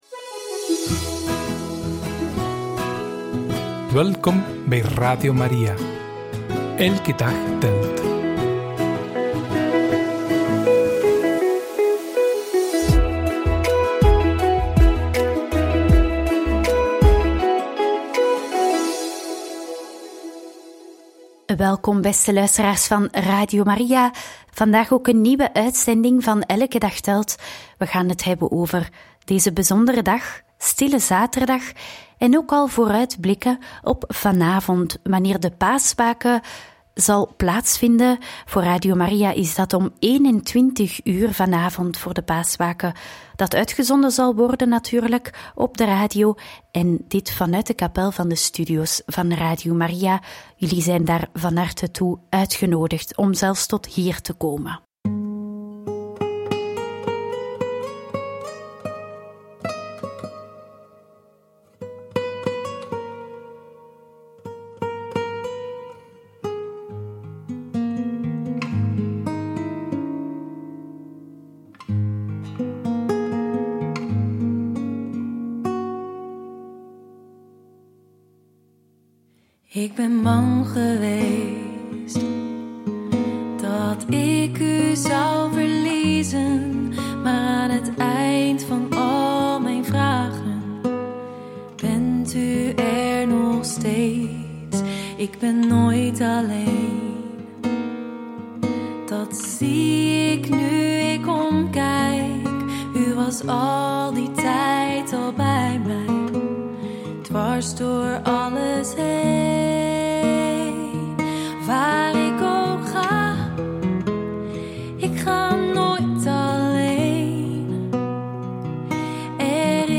Homilie